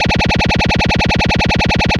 Laser Gun.wav